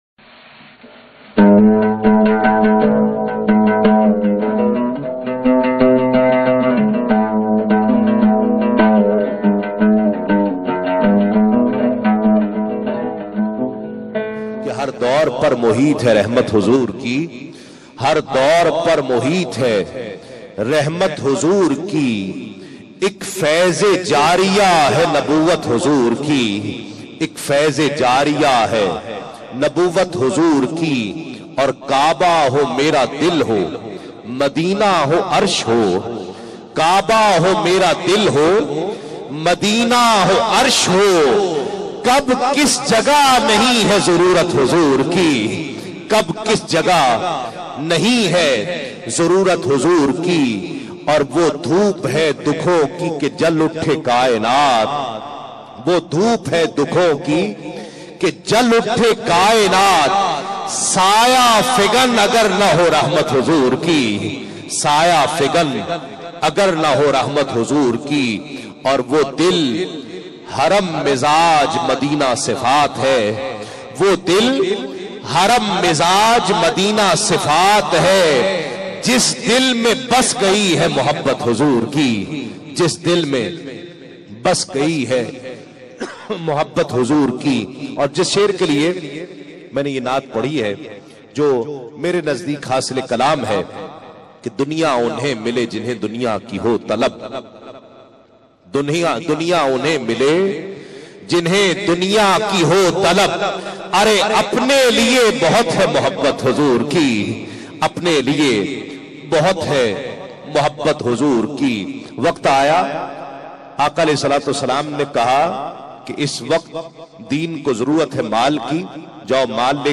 URDU NAAT